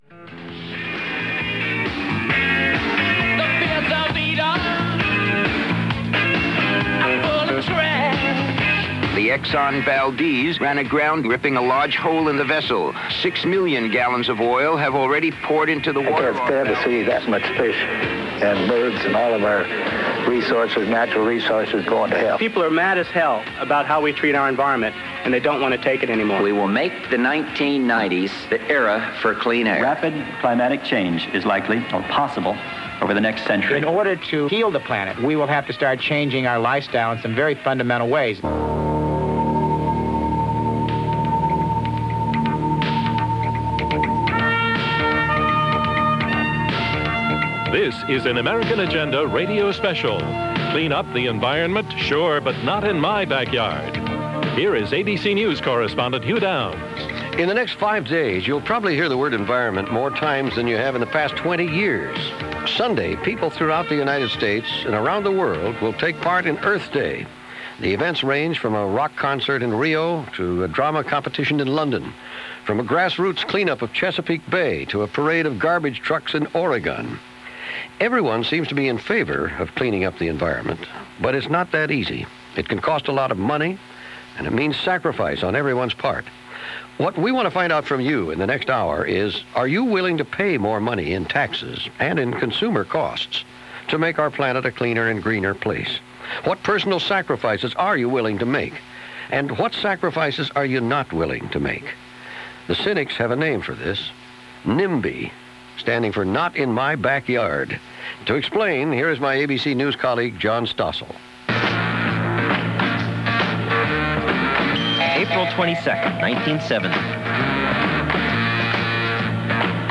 Earth Day 1990 - a report on the Environment, 20 years after the first Earth Day in 1970 - narrated by Hugh Downs for ABC Radio. - April 22, 1990